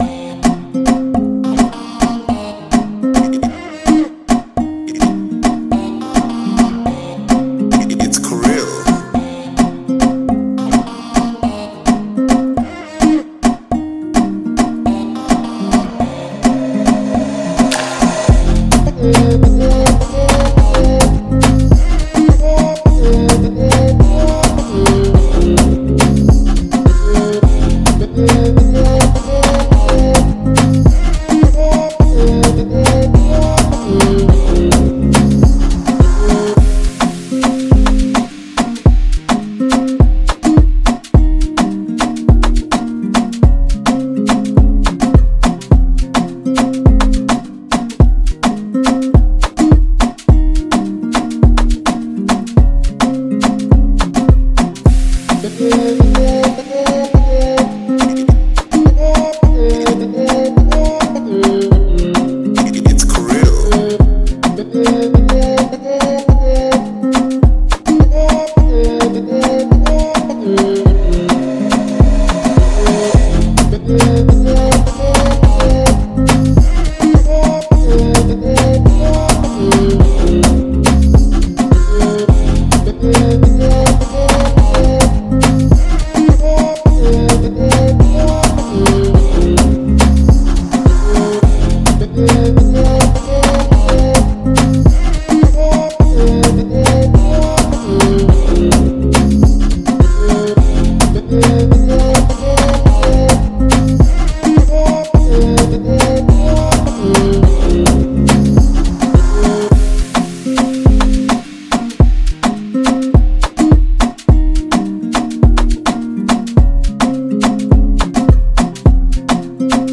March 5, 2025 Publisher 01 Gospel 0